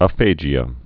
(ə-fājē-ə, -jə)